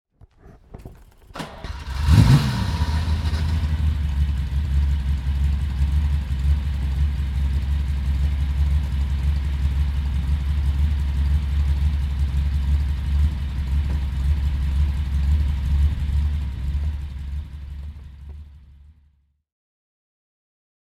Ferrari 365 GTB/4 "Daytona" (1973) - Starten und Leerlauf
Ferrari_Daytona_1973.mp3